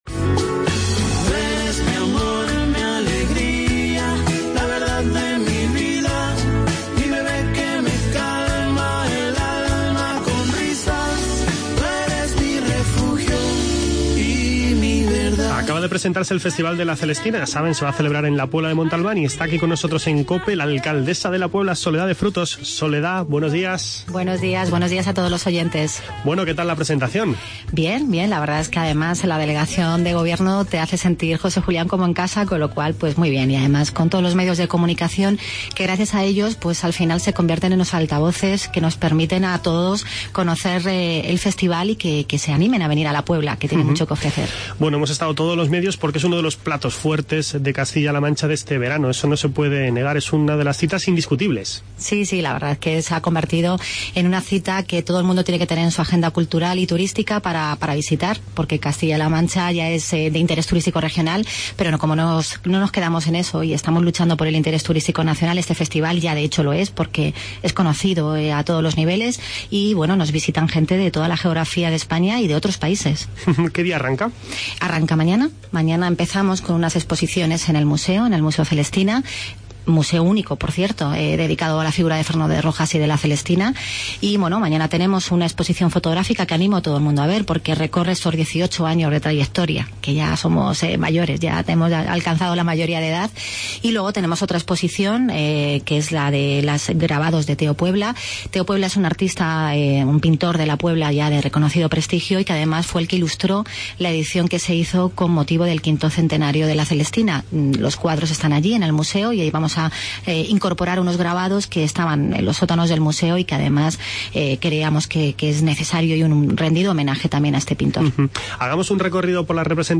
Charlamos con la alcaldesa de La Puebla de Montalbán, Soledad de Frutos.